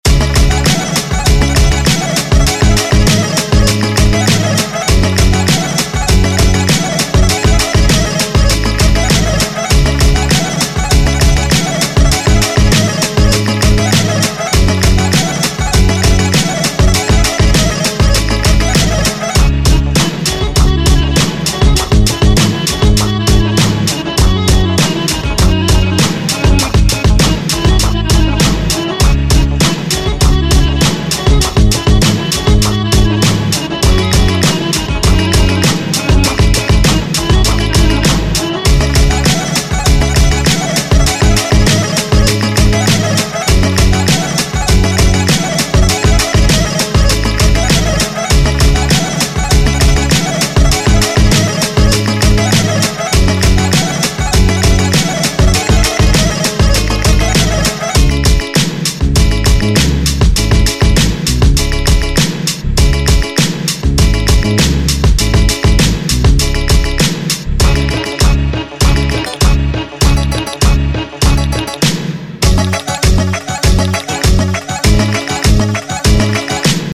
• Качество: 128, Stereo
восточные мотивы
Хип-хоп
без слов
инструментальные
быстрые